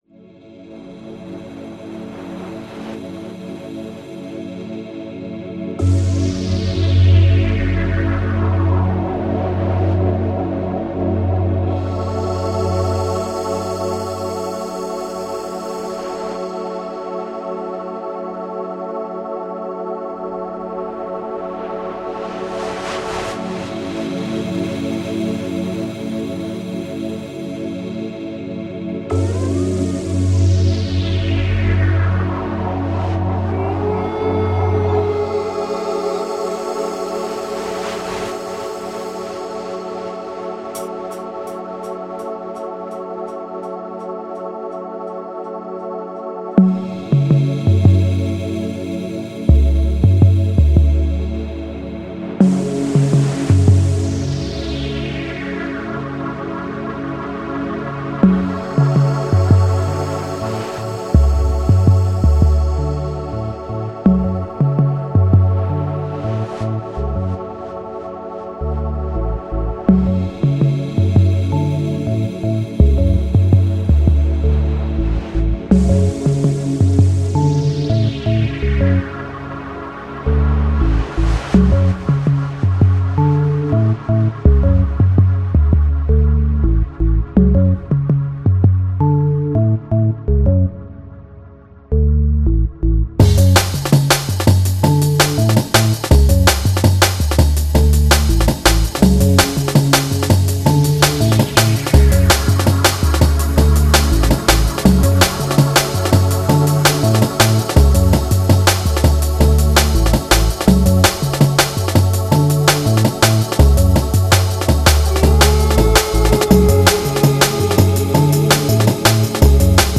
this specific type of music is called "bbcore", by the way
It's D&B.